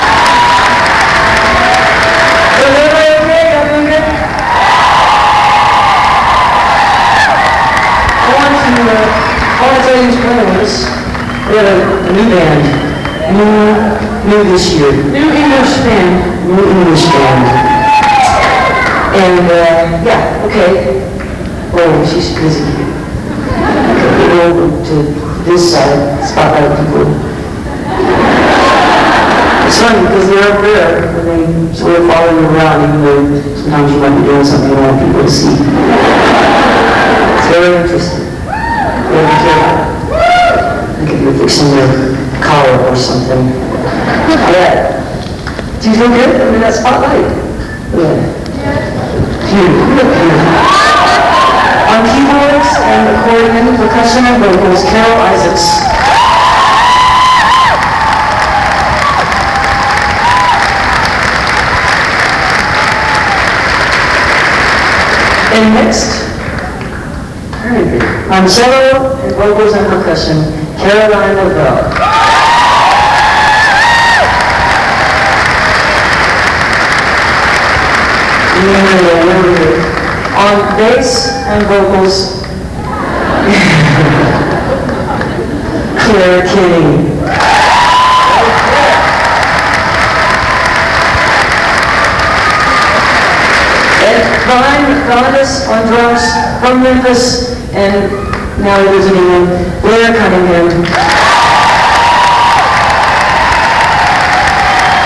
lifeblood: bootlegs: 1999-12-07: murat theatre - indianapolis, indiana
11. band introductions (1:45)
(this recording has some distortion)